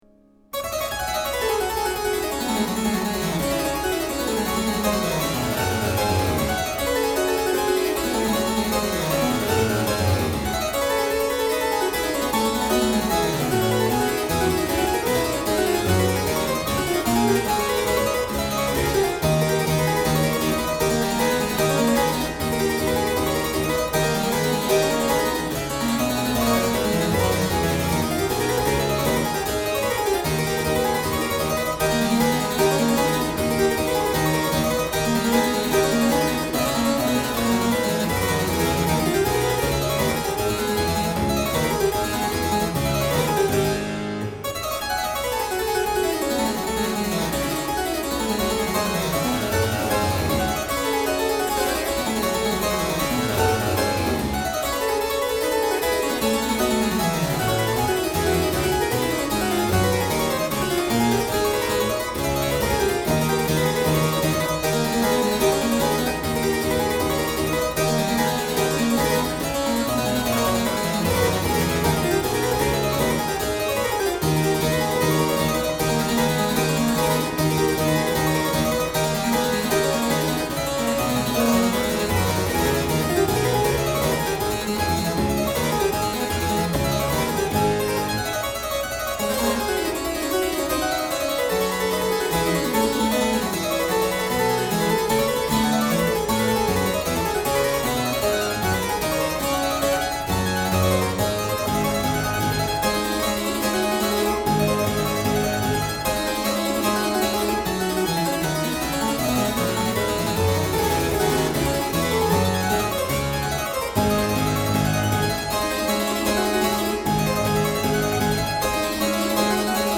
03_Sonata_K517_In_D_Minor.mp3